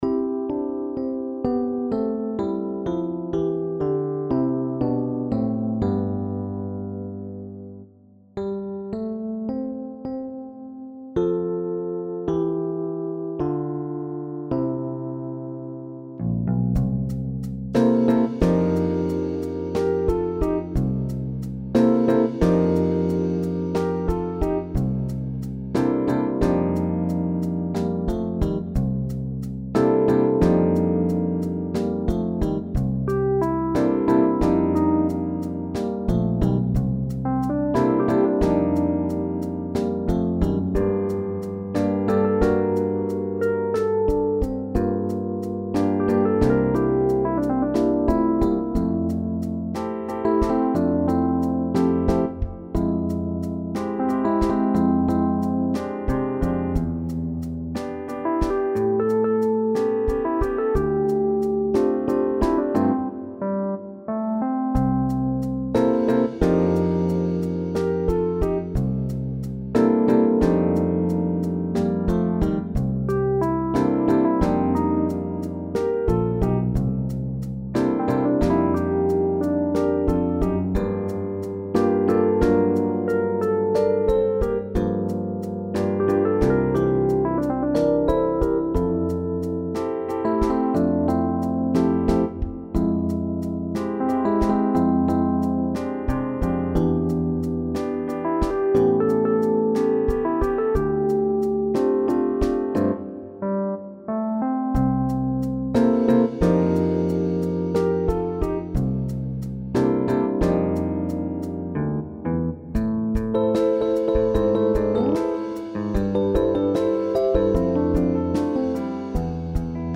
Jazz standard
SSAB met piano en solo | SATB met piano en solo